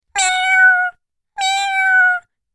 جلوه های صوتی
دانلود صدای کیتی از ساعد نیوز با لینک مستقیم و کیفیت بالا
برچسب: دانلود آهنگ های افکت صوتی انسان و موجودات زنده دانلود آلبوم صدای انواع گربه از افکت صوتی انسان و موجودات زنده